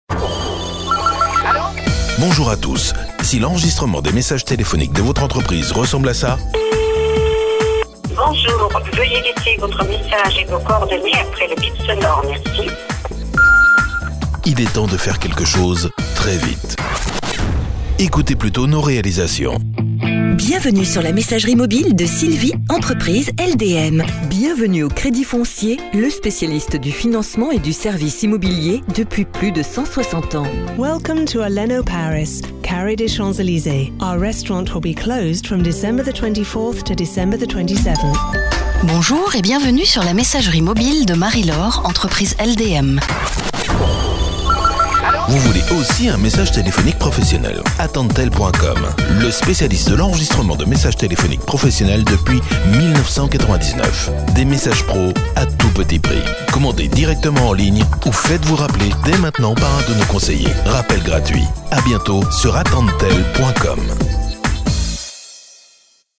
Exemple message téléphonique
Faites enregistrer DEUX messages téléphoniques 100% personnalisés par un studio d’enregistrement professionnel.
Une voix féminine ou masculine Française issue de notre casting en ligne.
validerUn mixage sur une musique libre de droits SACEM et SCPA issue de notre catalogue musical en ligne.